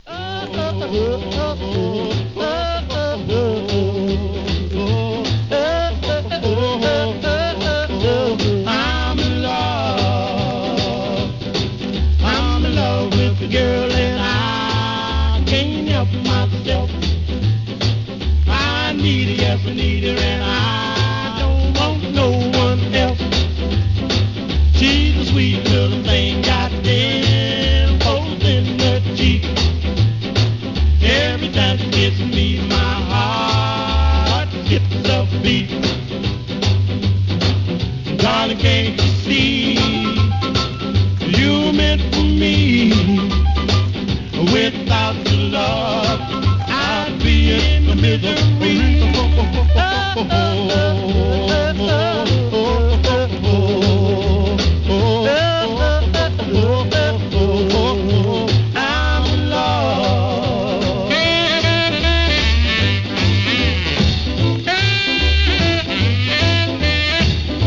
'60s OLDIES!!!